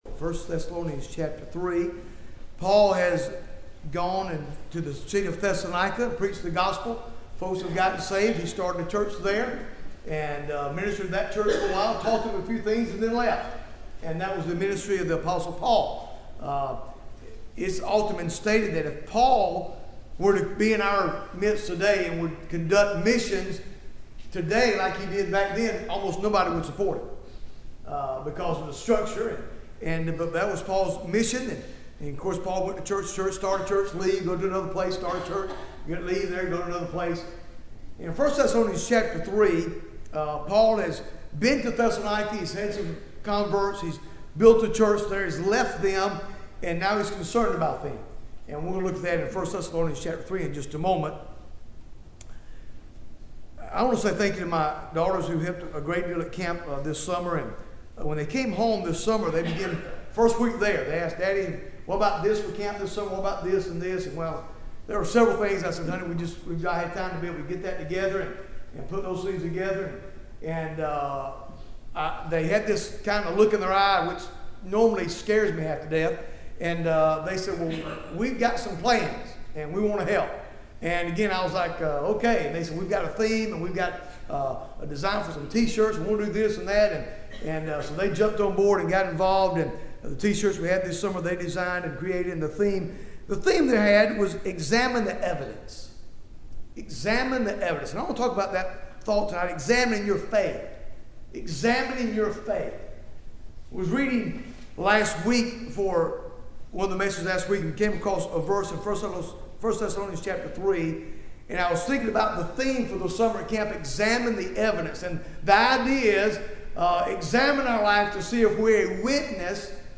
Bible Text: I Thessalonians 3 | Preacher